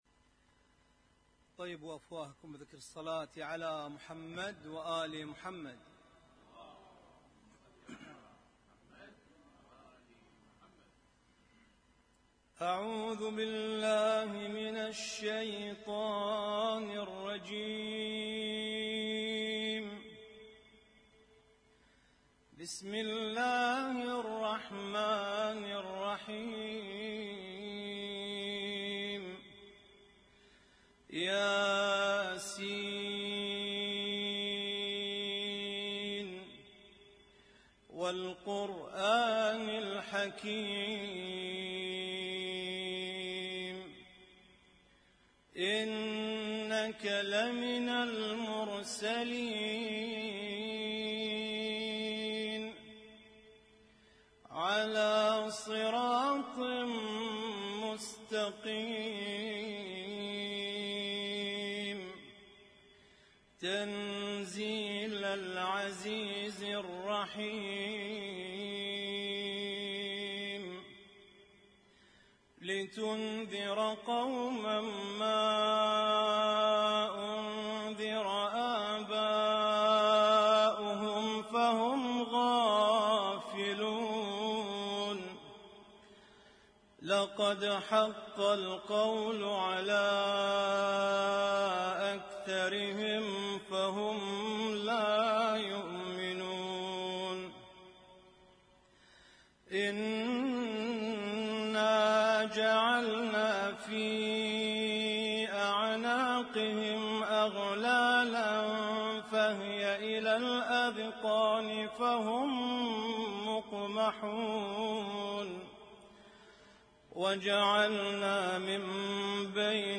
Husainyt Alnoor Rumaithiya Kuwait
اسم التصنيف: المـكتبة الصــوتيه >> القرآن الكريم >> القرآن الكريم - القراءات المتنوعة